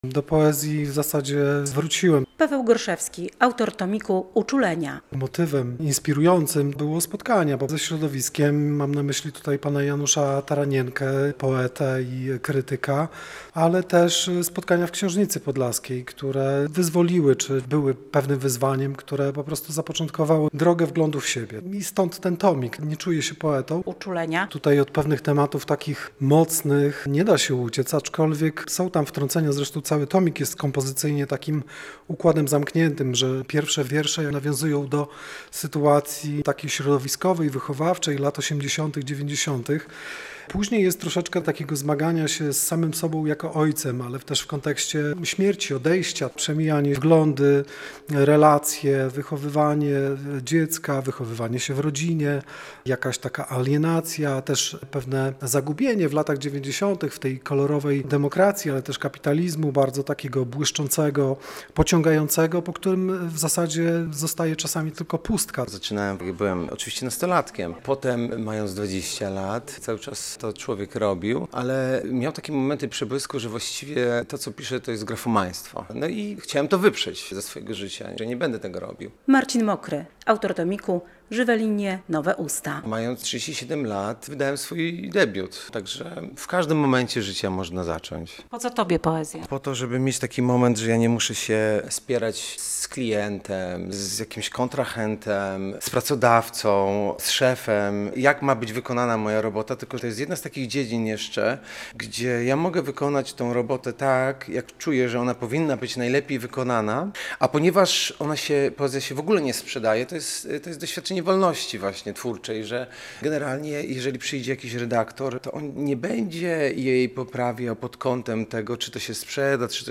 Do białostockiej Famy na spotkanie z poetami nominowanymi do Nagrody Literackiej im. Wiesława Kazaneckiego przyszło ok. 30 osób (to na 300 tysięczne miasto - około jednej dziesiątej promila).